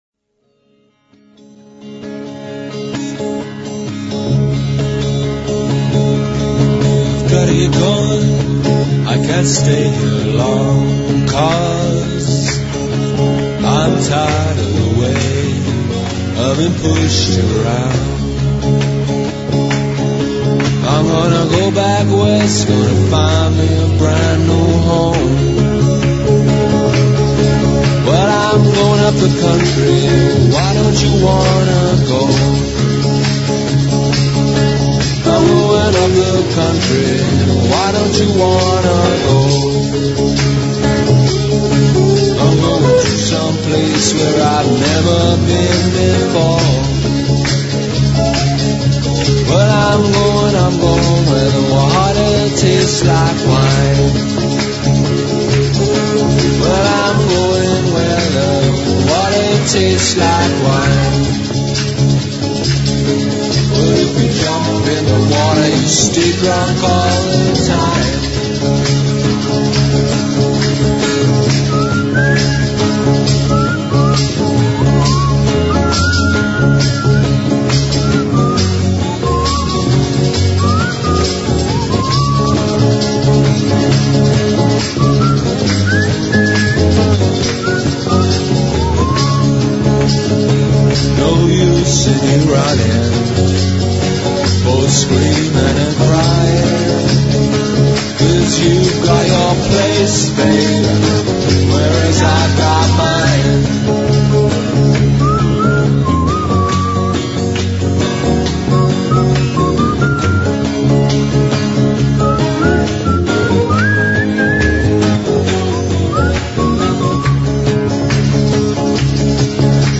Hablamos de su participación en la antología "McOndo"; (1996) de Alberto Fuguet y Sergio Gómez que presentó su escritura más allá de las fronteras de Uruguay. Leímos un fragmento de su cuento "Gritos y susurros"; en el que uno de sus personajes esboza una teoría sobre cómo el modo de comer un chivito puede delatar a alguien.